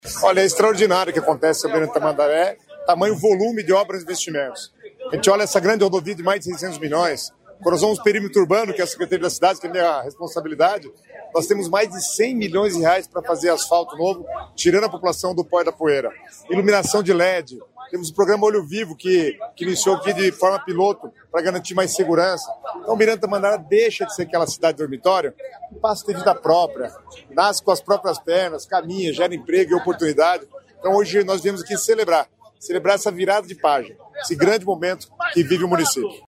Sonora do secretário Estadual das Cidades, Guto Silva, sobre a entrega da duplicação do Lote 2 da Rodovia dos Minérios